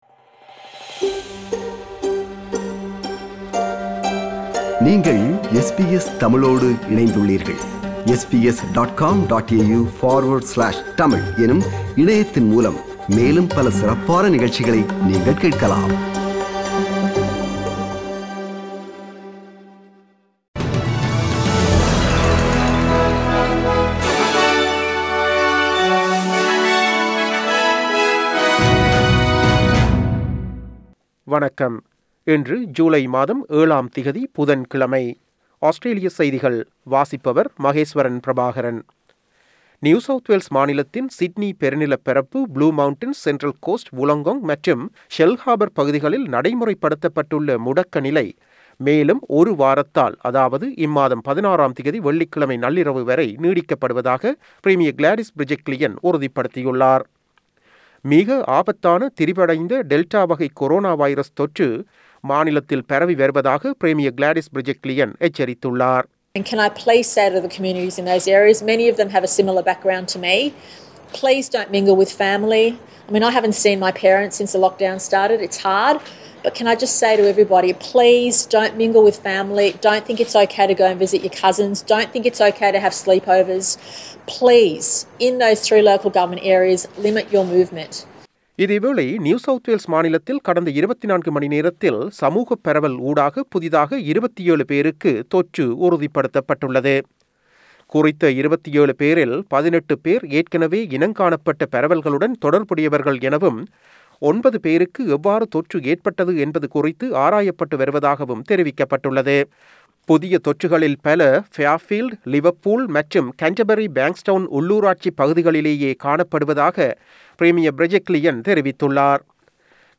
Australian news bulletin for Wednesday 07 July 2021.